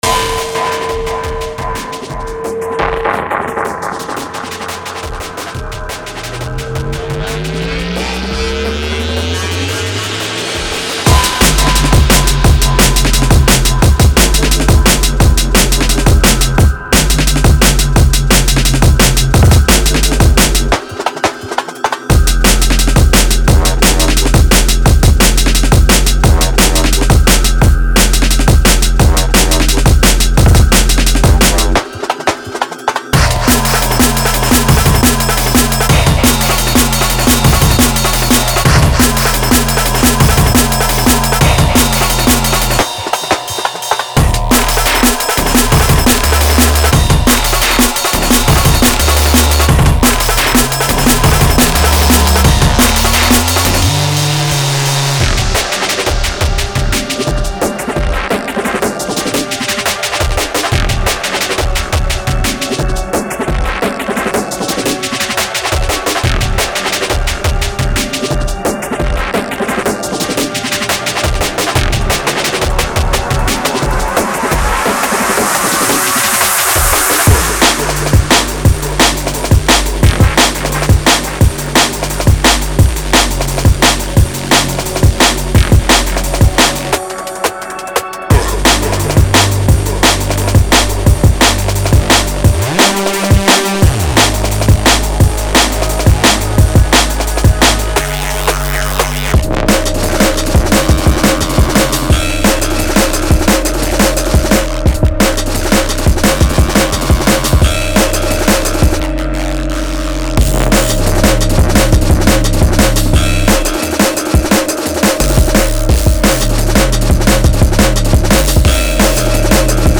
ビートはパンチがありダイナミックで、あらゆるトラックを前へと推進します。
ベースループは重厚でパワフルであり、楽曲の完璧な土台となります。
また、ビートはひねりの効いたダイナミックな仕上がりで、シンセはキャラクターに富み、トラックに独自のフレイバーを与えます。
デモサウンドはコチラ↓
Genre:Drum and Bass
174 BPM